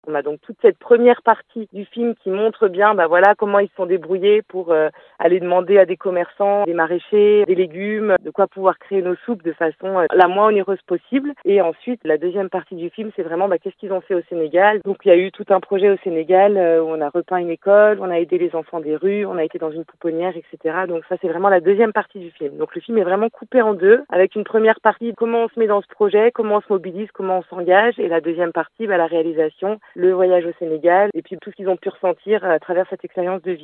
une des éducatrices